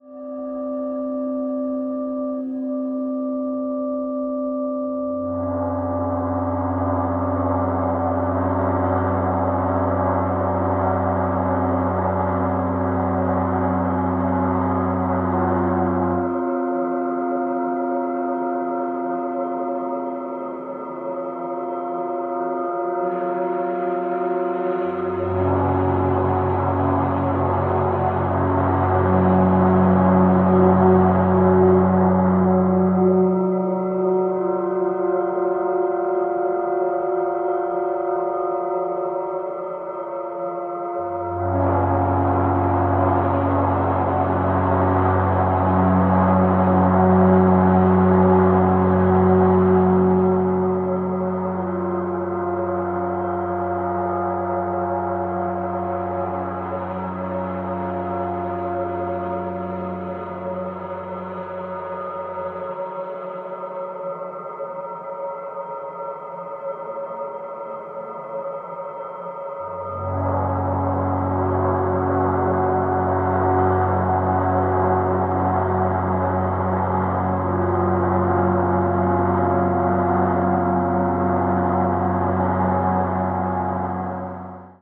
patiently unfolding electro-acoustic constructions